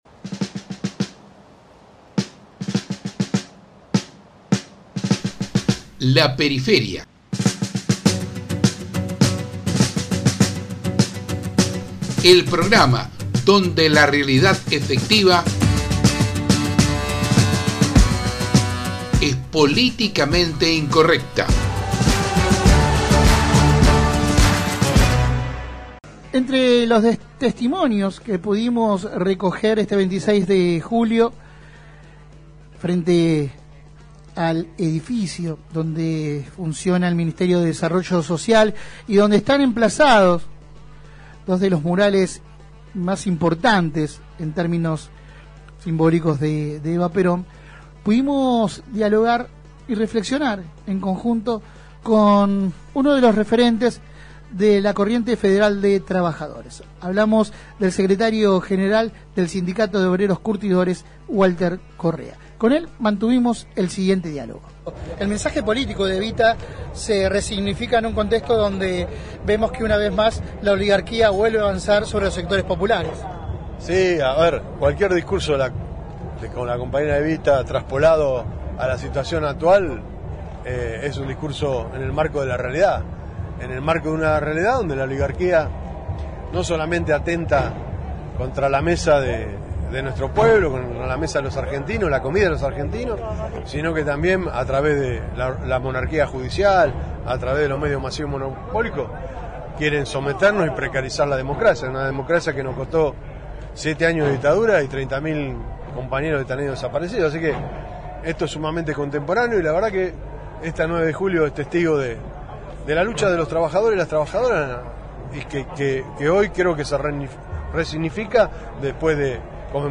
El Secretario General del Sindicato de obreros curtidores fue entrevistado por La Periferia durante la movilización en recuerdo de la abanderada de los humildes.